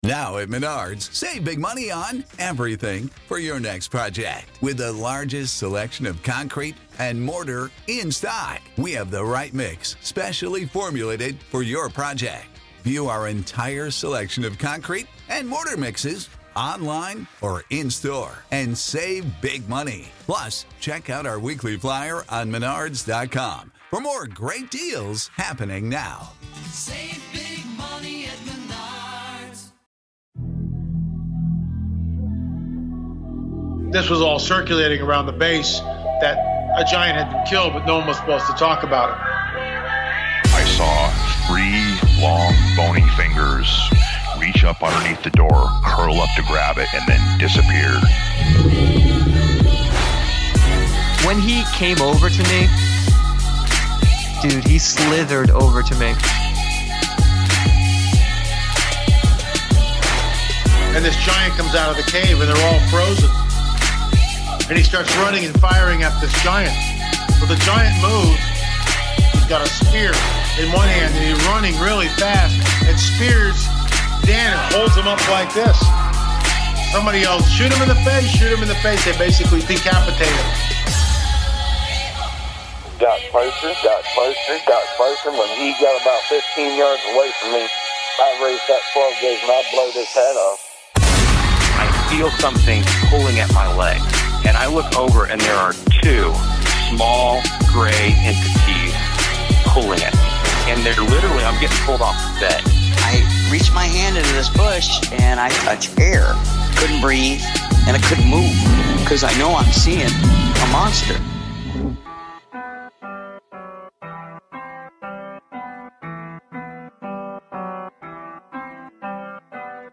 During his interview, there is a surprising disturbance on the recording that may be an EVP!